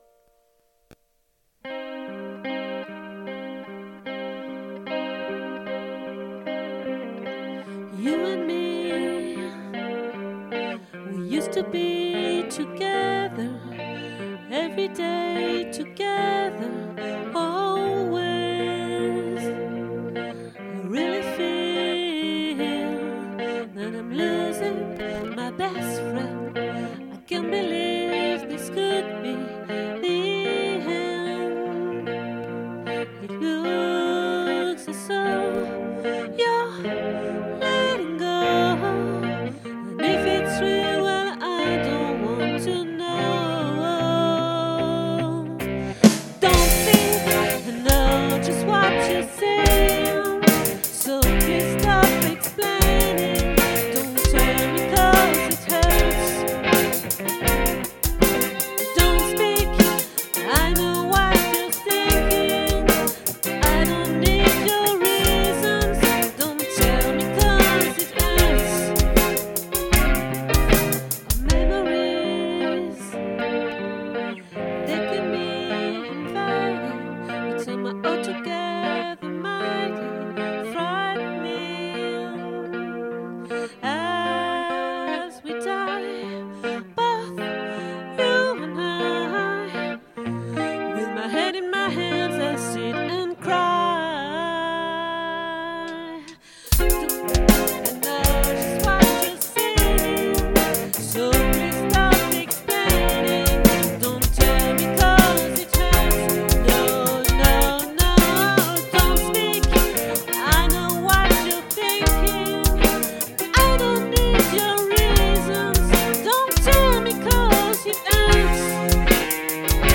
🏠 Accueil Repetitions Records_2024_01_03